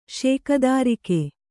♪ śekadārike